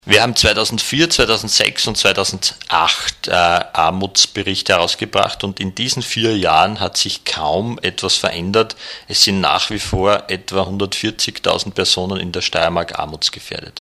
im O-Ton